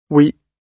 Transcription and pronunciation of the word "we" in British and American variants.